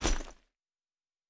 pgs/Assets/Audio/Fantasy Interface Sounds/Bag 11.wav at master
Bag 11.wav